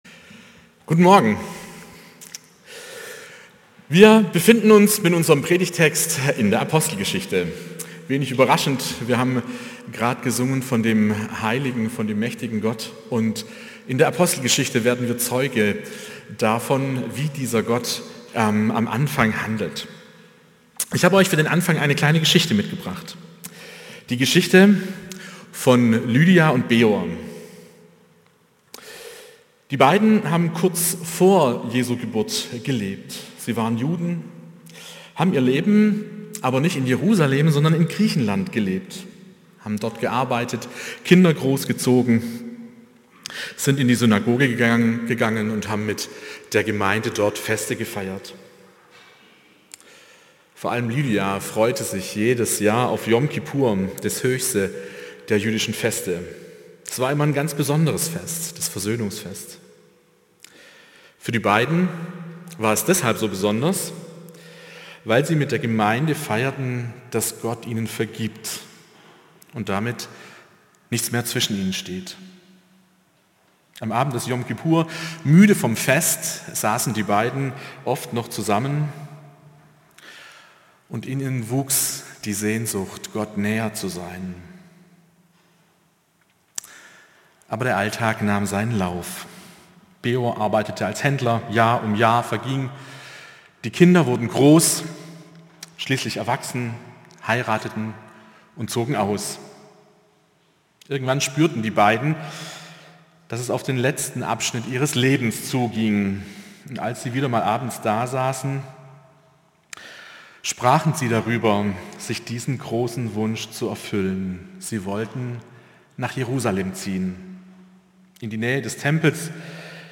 Predigten aus einANDERERGottesdienst